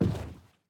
Minecraft Version Minecraft Version 1.21.5 Latest Release | Latest Snapshot 1.21.5 / assets / minecraft / sounds / entity / boat / paddle_land3.ogg Compare With Compare With Latest Release | Latest Snapshot
paddle_land3.ogg